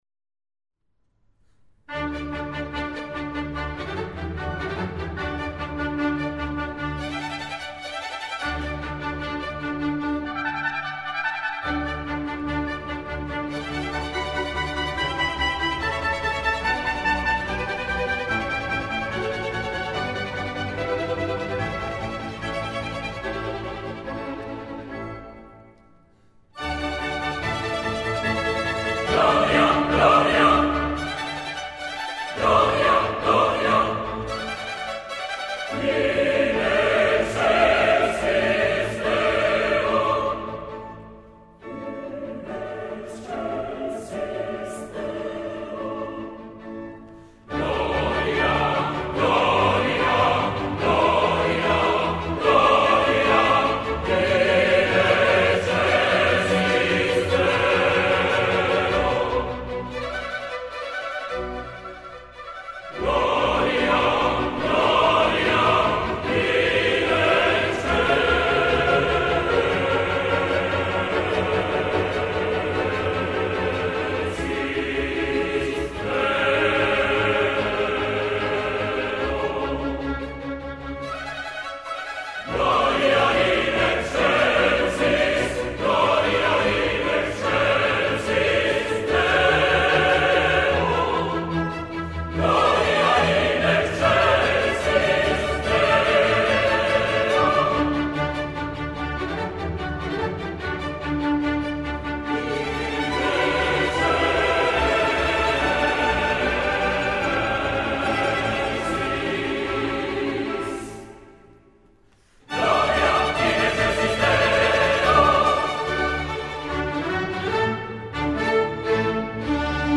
gloria_original_concert.mp3